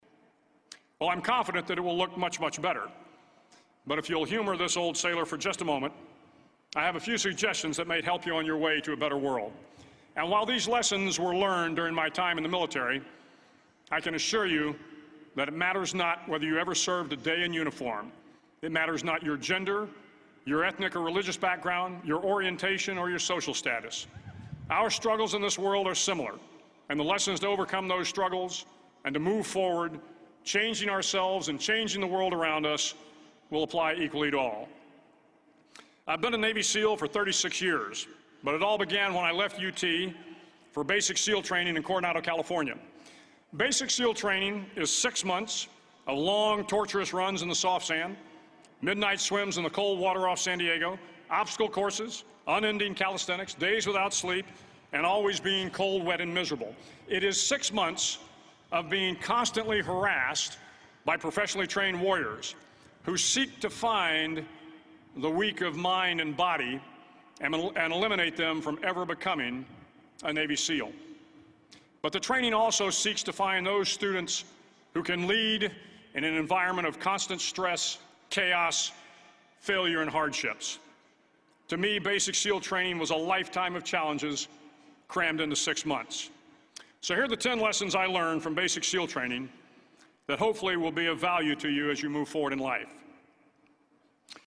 公众人物毕业演讲 第230期:威廉麦克雷文2014德州大学演讲(3) 听力文件下载—在线英语听力室